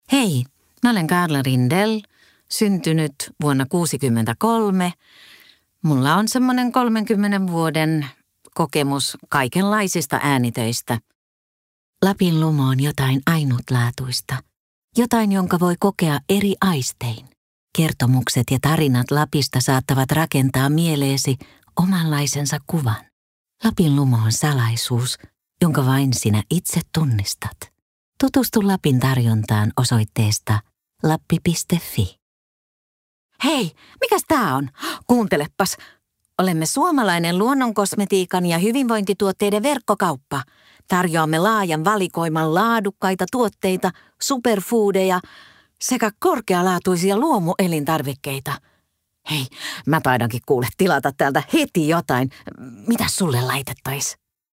Voice color: neutral
Ääninäyte Suomi